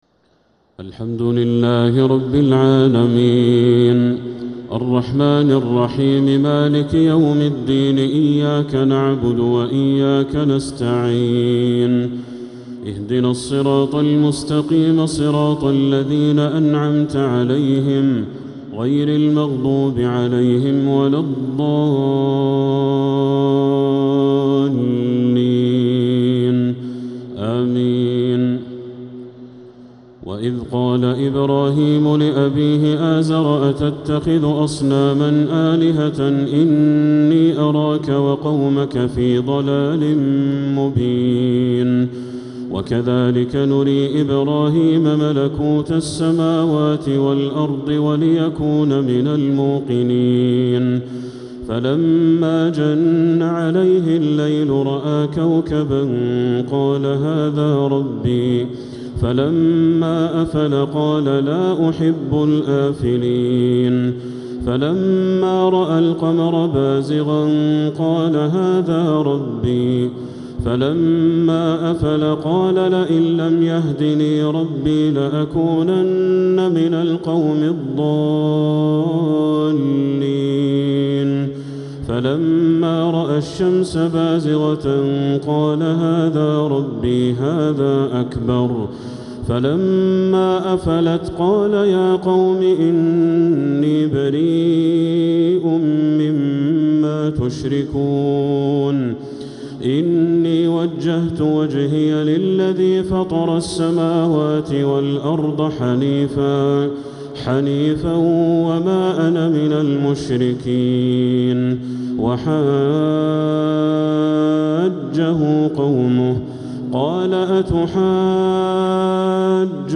تراويح ليلة 10 رمضان 1447هـ من سورة الأنعام (74-115) | Taraweeh 10th niqht Ramadan Surat Al-Anaam 1447H > تراويح الحرم المكي عام 1447 🕋 > التراويح - تلاوات الحرمين